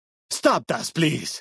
Dead Horses pidgin audio samples Du kannst diese Datei nicht überschreiben.